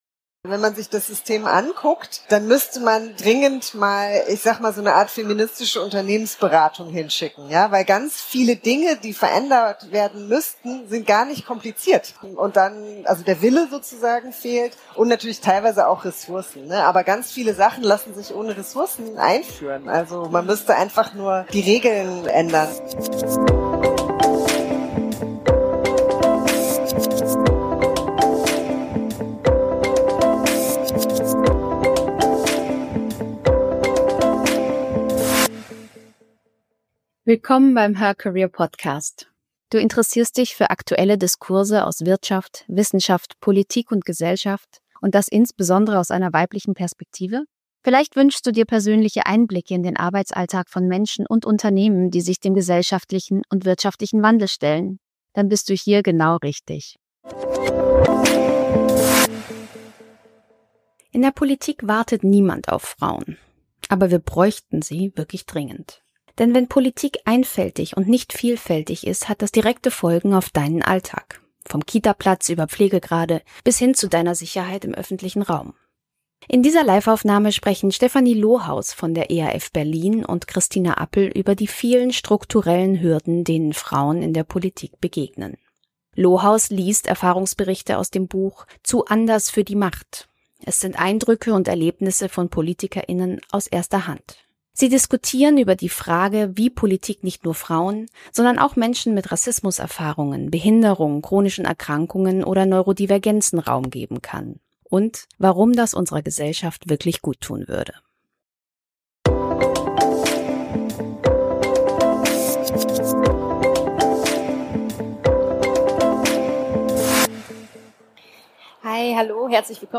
Die Folge schildert, warum der Politikbetrieb immer noch für privilegierte Männer ohne Fürsorgepflichten gebaut ist und was das für deinen Alltag als Frau und Mutter bedeutet – von Kita-Plätzen über Pflegeverantwortung, bis zur Sicherheit im öffentlichen Raum. Gleichzeitig versucht das Gespräch zu zeigen, dass viele Frauen trotz dieser Bedingungen im Politikbetrieb bleiben: überparteiliche Allianzen, sichtbare Veränderungen vor Ort in der Kommunalpolitik und Netzwerke, die speziell Frauen und marginalisierte Personen stärken.